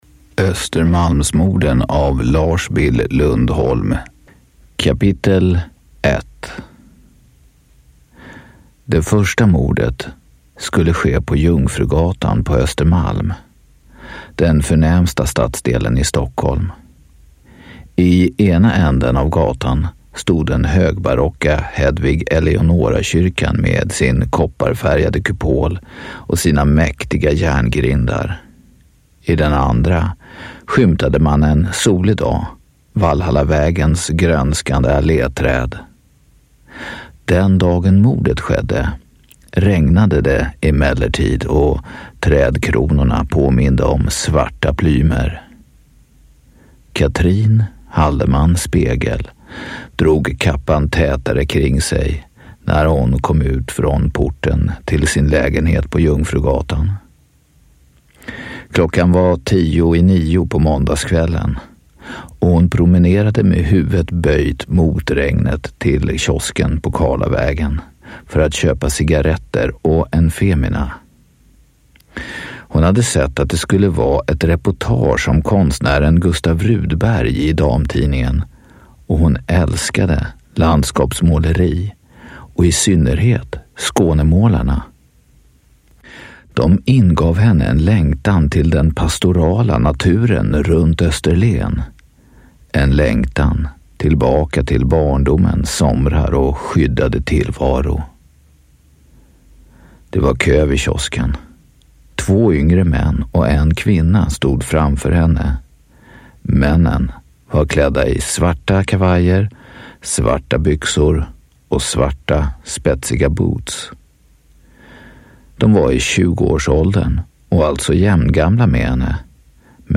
Östermalmsmorden / Ljudbok